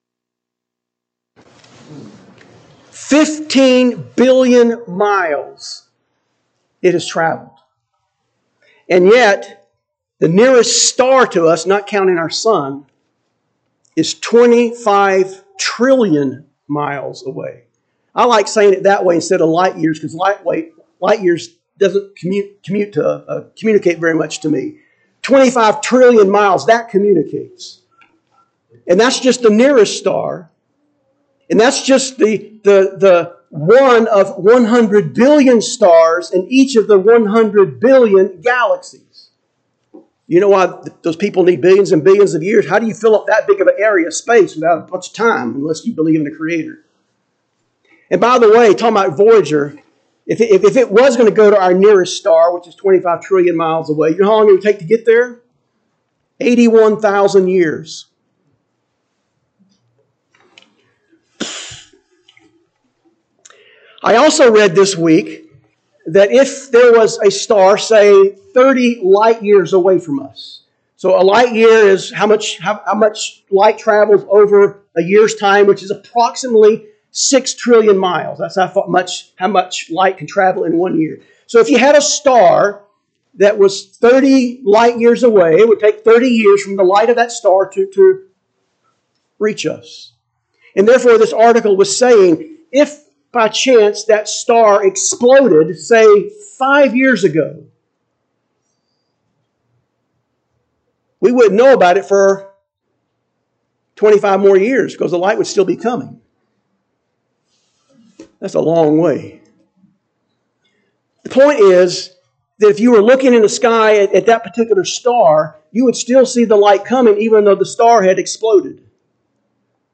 Note: the opening 1 1/2 minutes is missing